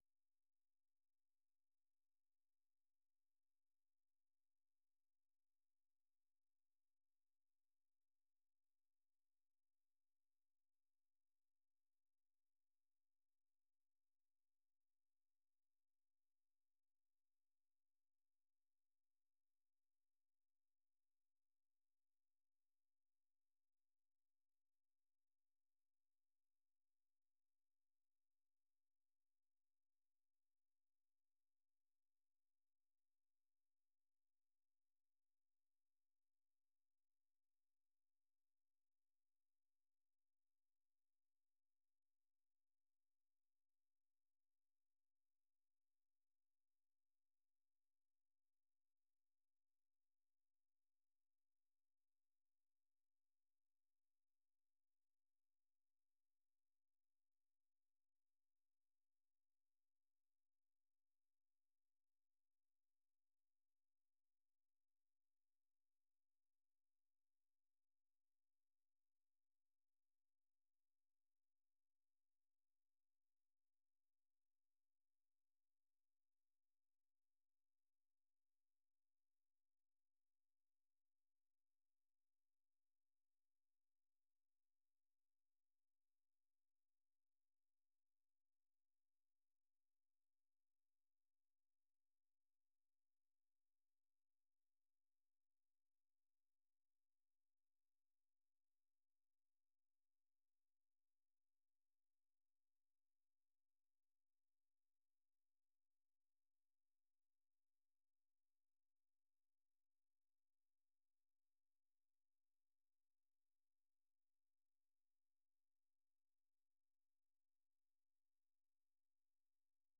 세계 뉴스와 함께 미국의 모든 것을 소개하는 '생방송 여기는 워싱턴입니다', 아침 방송입니다.